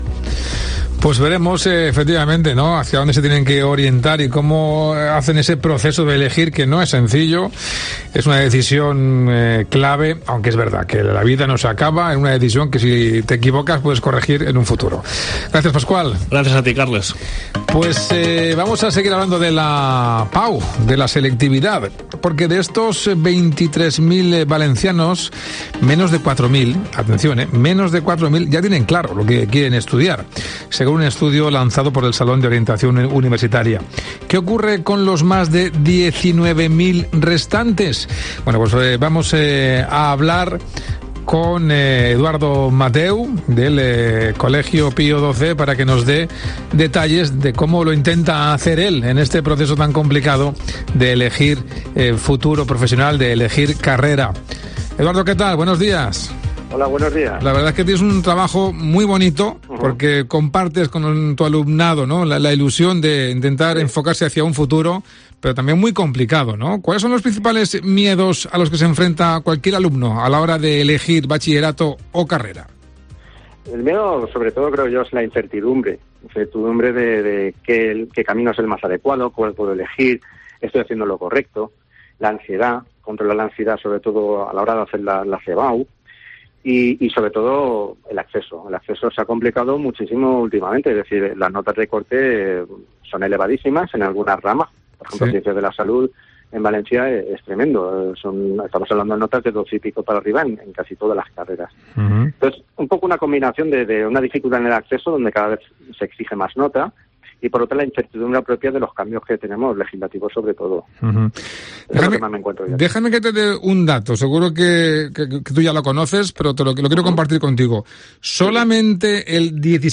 Mediodía COPE MÁS Valencia | Entrevista orientador de segundo bachiller sobre la EBAU